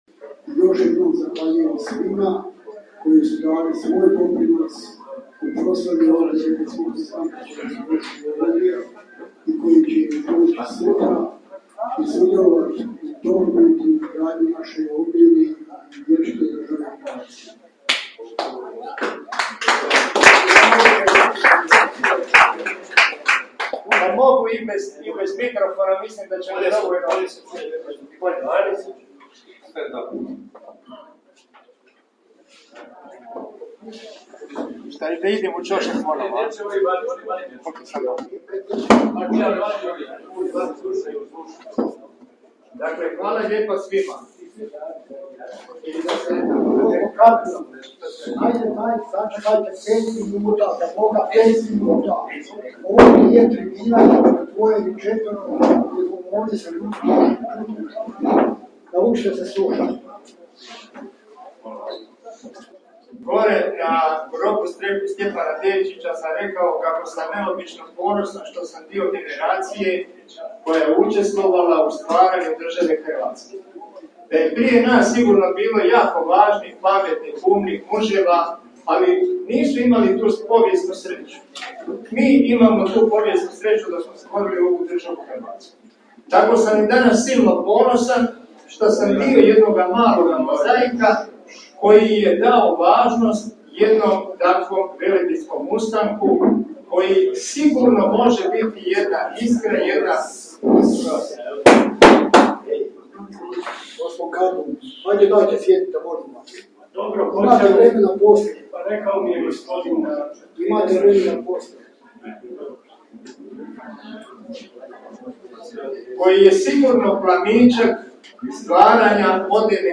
Okrugli stol - sprdnja i ponižavanje!